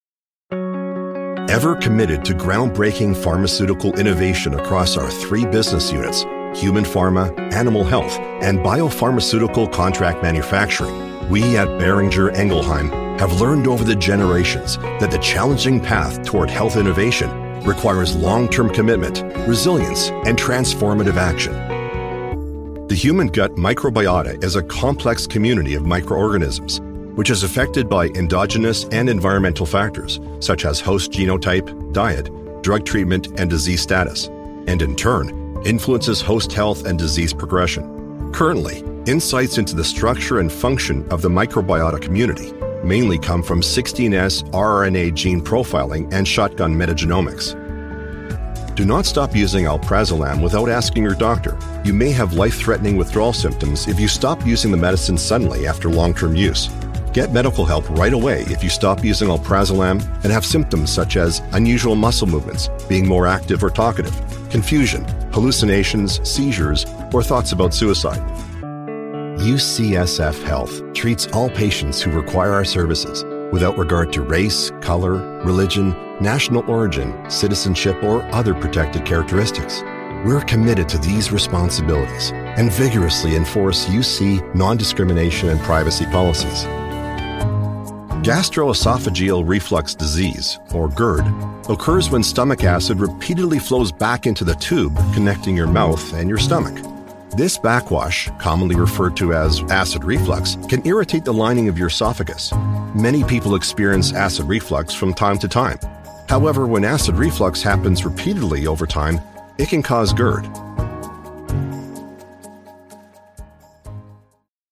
English (Canadian)
Medical Narration
CAD E100S large diaphragm condenser microphone, Steinberg UR22 interface, Mogami cables, custom-built recording studio, Mac Mini running Adobe Audition CC and full connectivity including SourceConnect Standard for directed sessions.
BaritoneBassDeepLow